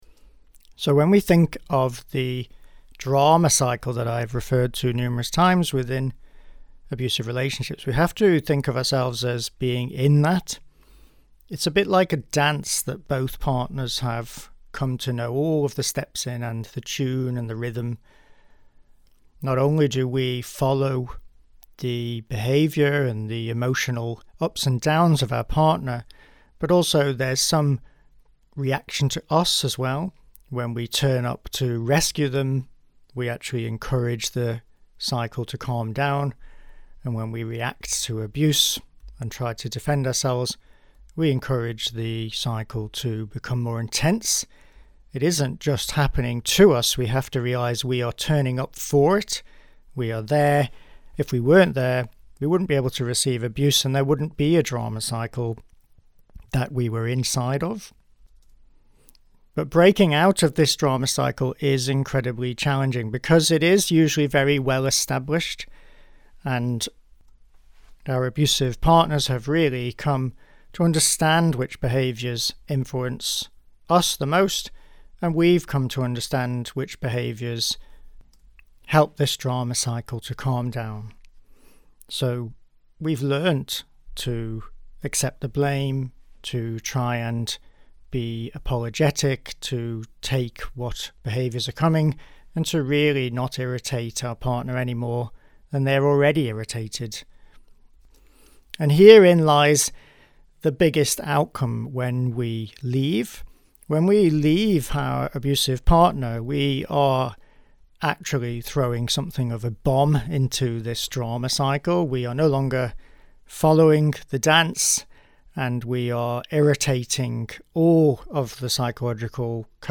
LISTEN HERE: This blog is extracted from one of the audio lessons in the Get Out Get Love programme, which you can listen to here - if you prefer.